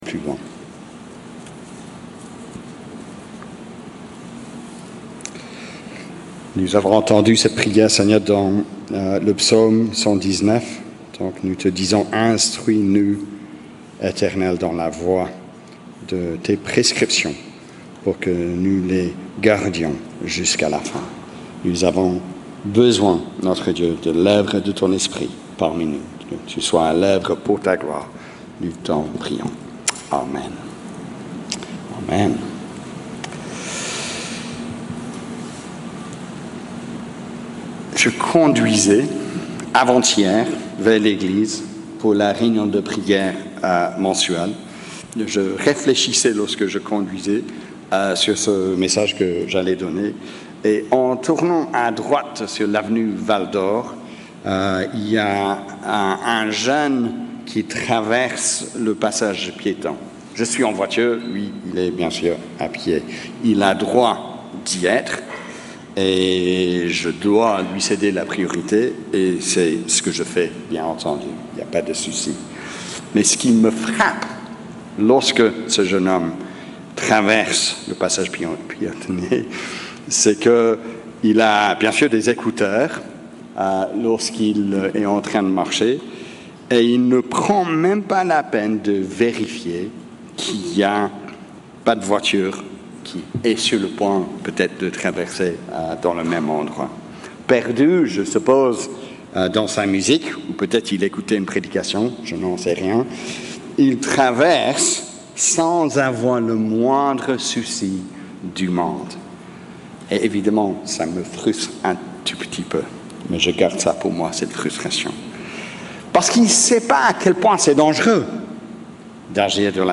Predication-du-culte-du-4-mai-2025.mp3